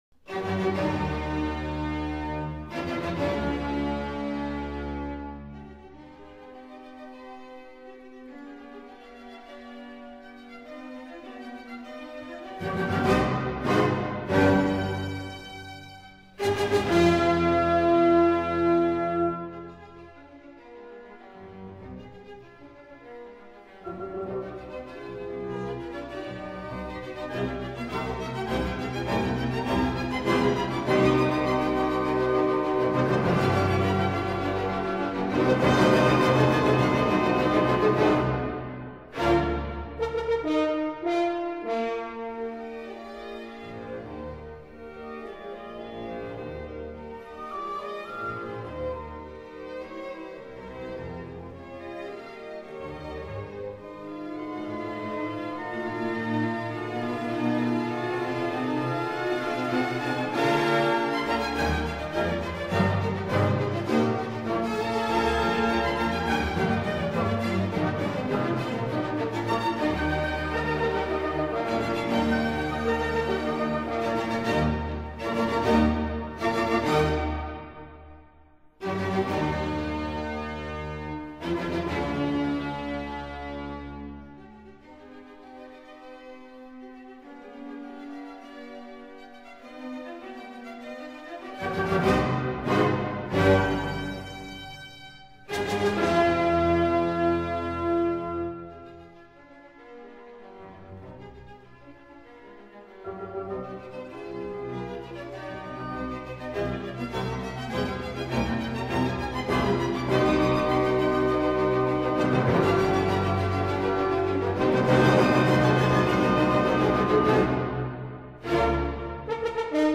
Beethoven-Symphony-No.-5-in-C-Minor.mp3